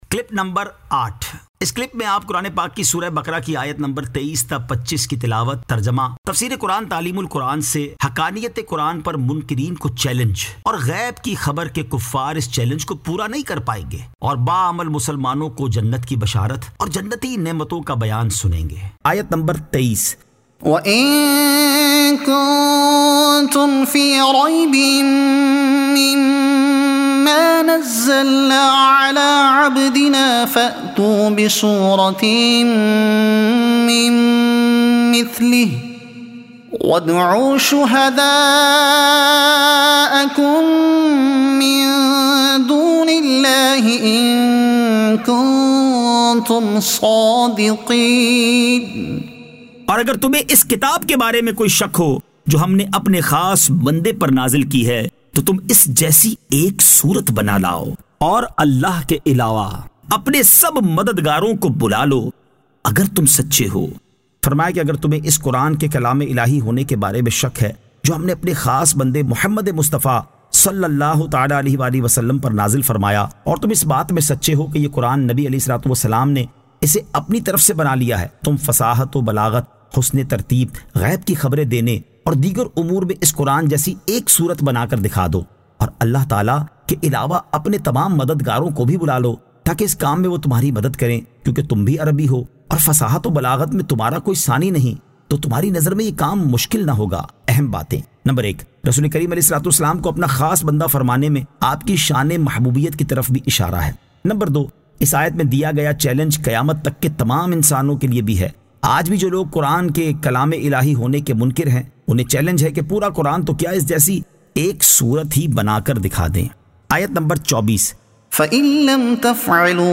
Surah Al-Baqara Ayat 23 To 25 Tilawat , Tarjuma , Tafseer e Taleem ul Quran